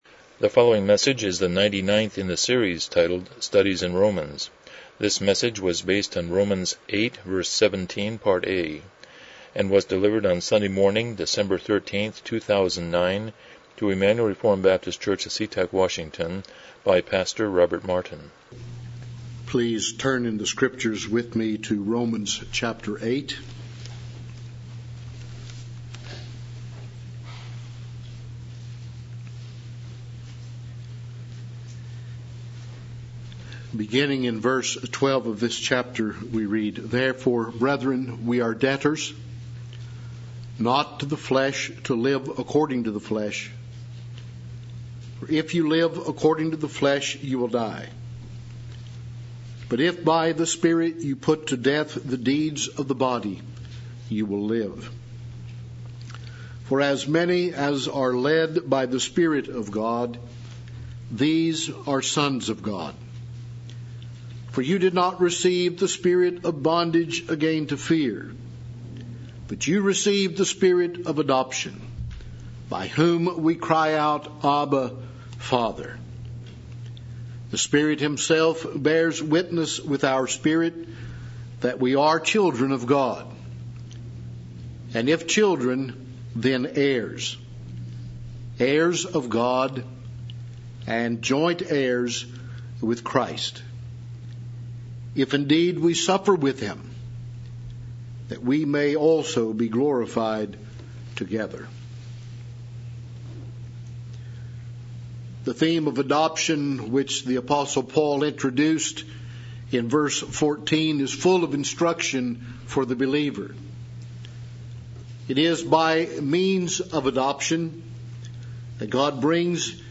Romans 8:17 Service Type: Morning Worship « 56 The Second Commandment